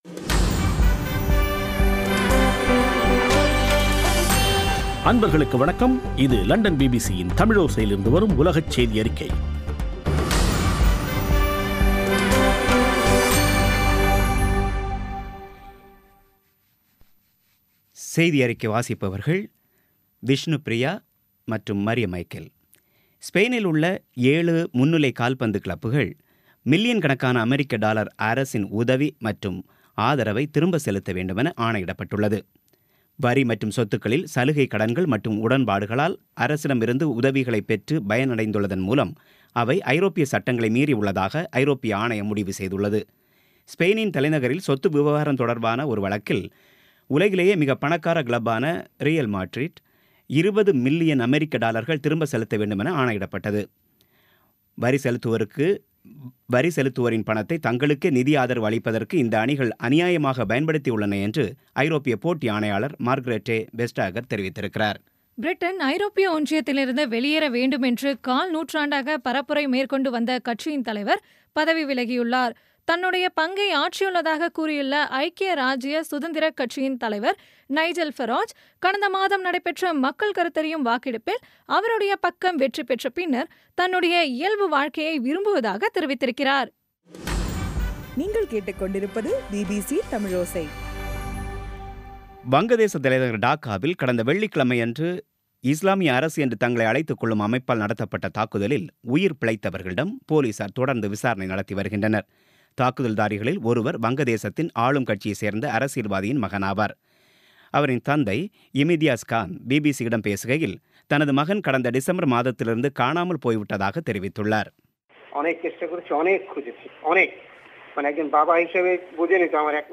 இன்றைய (ஜூலை 4-ஆம் தேதி ) பிபிசி தமிழோசை செய்தியறிக்கை